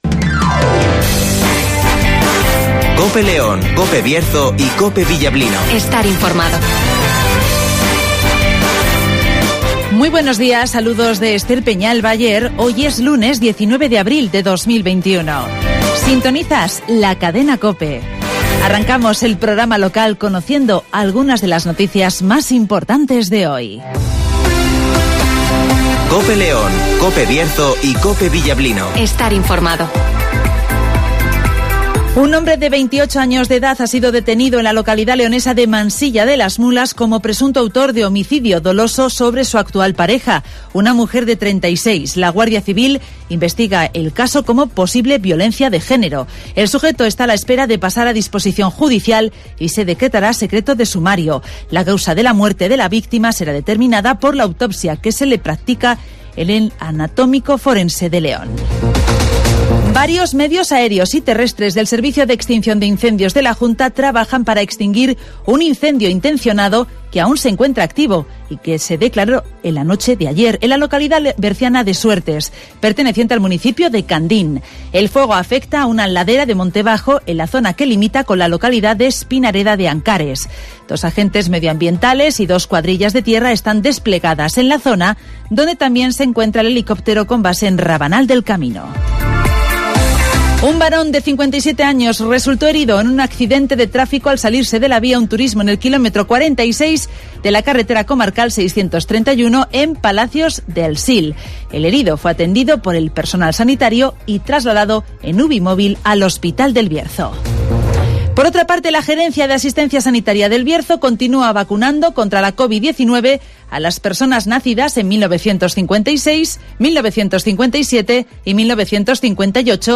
Avance informativo, El Tiempo (Neucasión) y Agenda (Carnicerías Lorpy)